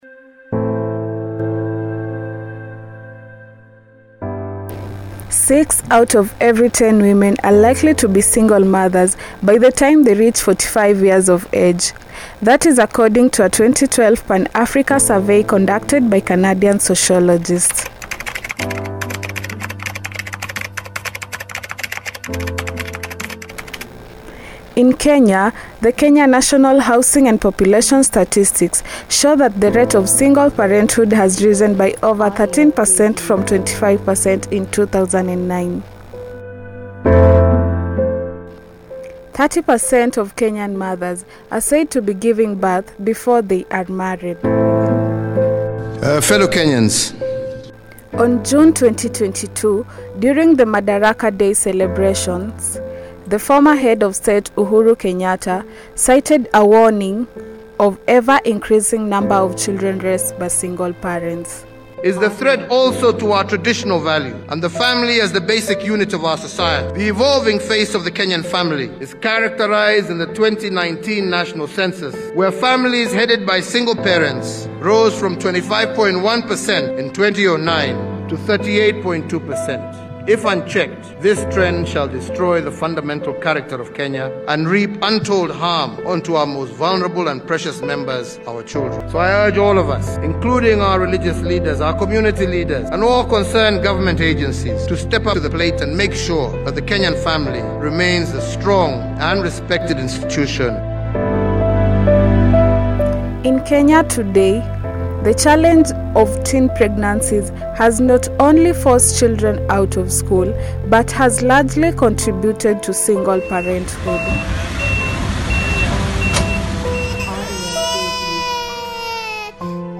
Radio Documentary:The Price Of Motherhood-Six Out of Every Ten Women In Kenya are likely to Be Single Mothers
RADIO-DOCUMENTARY-THE-PRICE-OF-MOTHERHOOD.mp3